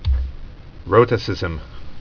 (rōtə-sĭzəm)